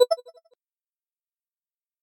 Звуки авторизации